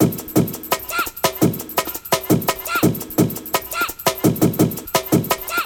Tag: 170 bpm Drum And Bass Loops Drum Loops 975.40 KB wav Key : Unknown